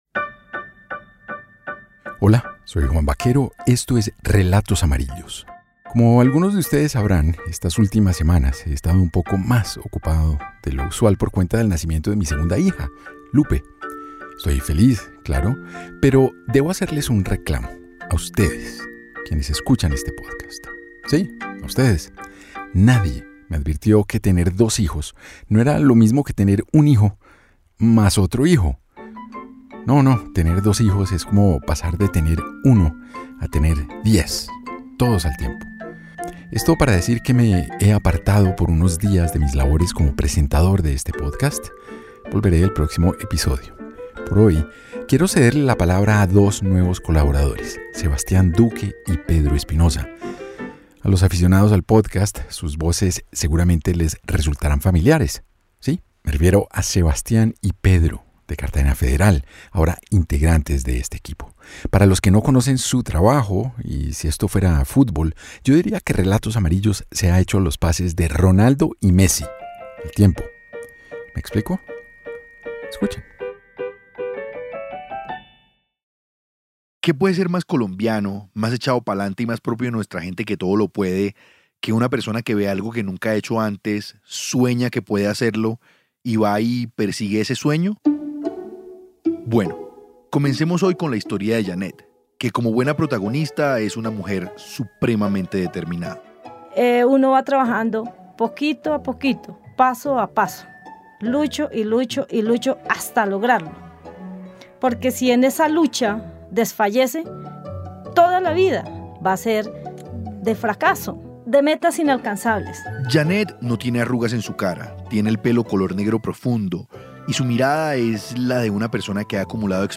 ..Podcast narrativo.
¿Vale la pena luchar por nuestras aspiraciones más profundas? En este capítulo de Relatos amarillos, cinco taxistas nos mostrarán que materializar los sueños más pequeños y sin límite alguno es necesario para darle sentido a nuestras vidas.